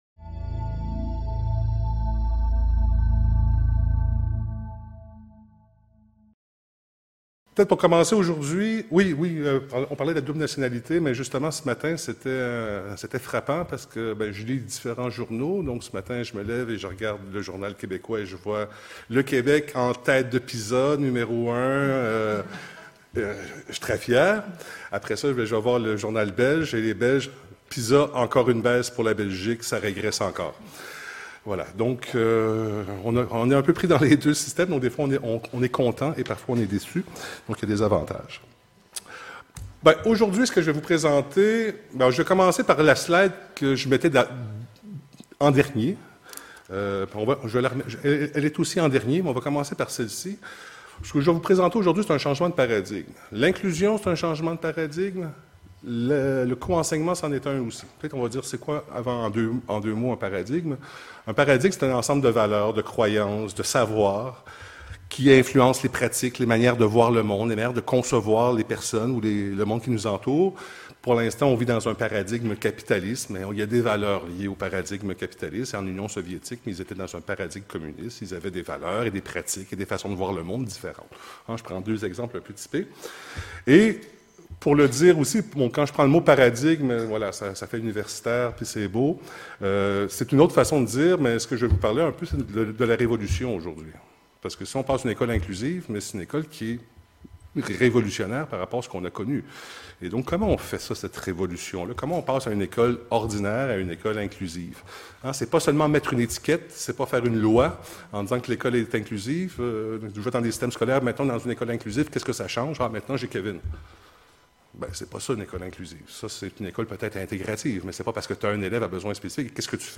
Dans le cadre de cette conférence, nous situerons tout d’abord le coenseignement comme modèles de service : consultation, co-intervention, coenseignement (Trépanier, 2005 ; Tremblay, 2012). Les avantages et les limites de chaque modèle seront abordés et la recherche scientifque soutenant l’utilisation de chaque modèle de service sera analysée.
Enfn, une discussion sera proposée avec les participants sur les limites du coenseignement et sur la redéfnition de ce qu’est un enseignement spécialisé dans un contexte inclusif.